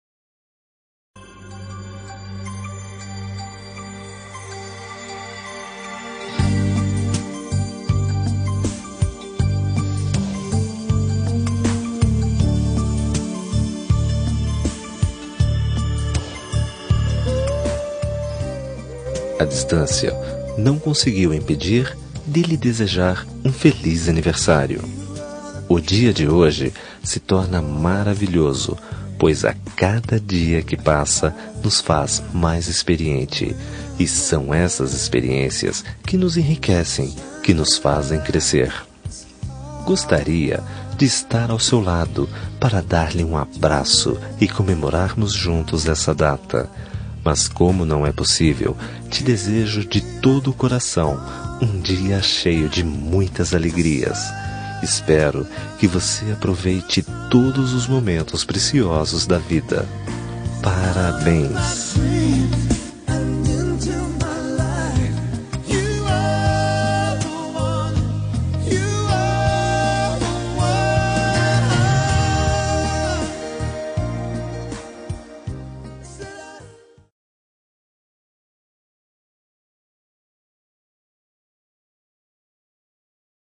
Aniversário de Pessoa Especial – Voz Masculina – Cód: 1927 – Distante